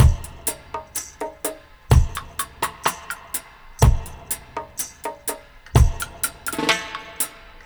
62-FX+PERC3.wav